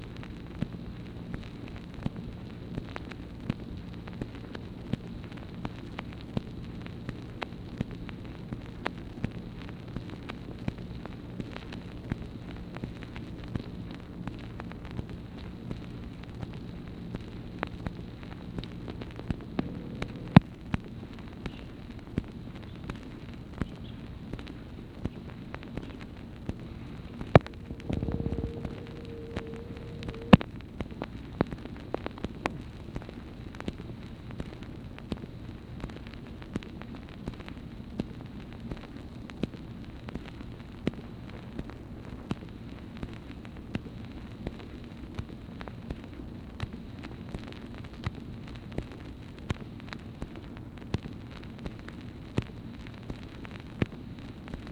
MACHINE NOISE, May 2, 1964
Secret White House Tapes | Lyndon B. Johnson Presidency